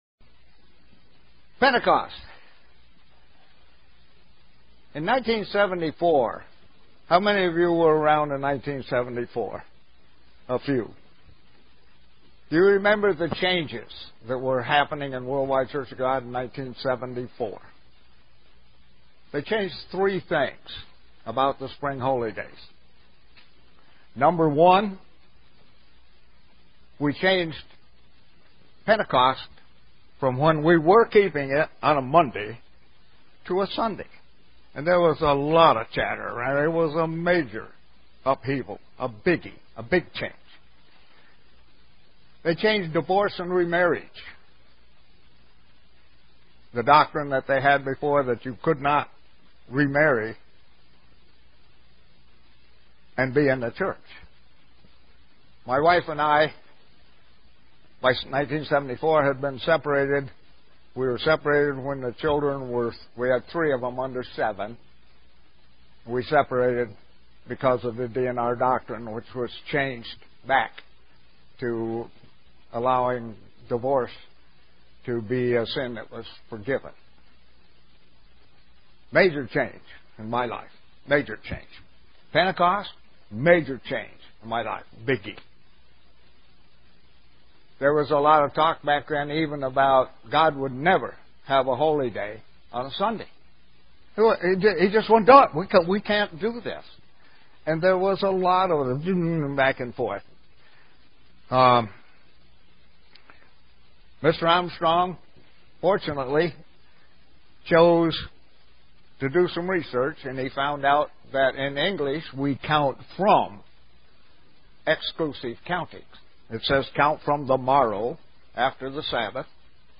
Given in Buffalo, NY Elmira, NY
Is the date it is to be kept important SEE VIDEO BELOW UCG Sermon Studying the bible?